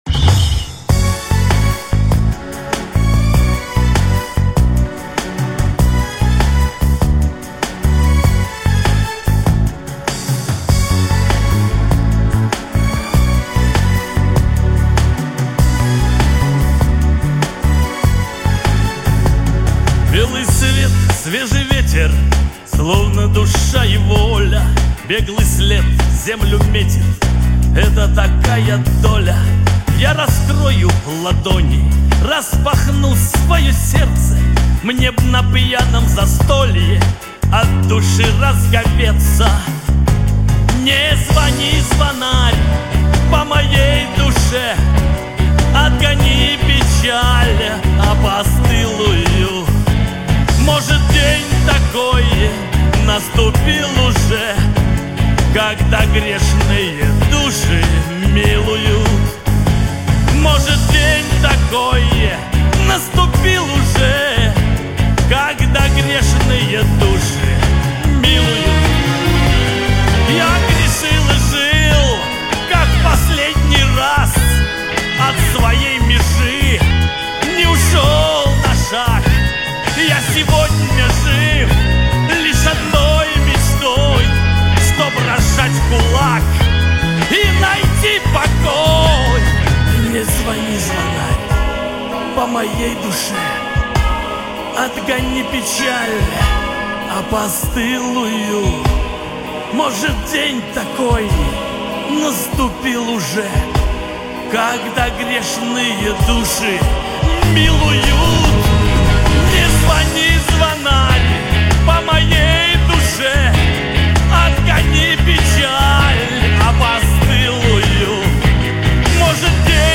Небольшая подборка прекрасного шансонье...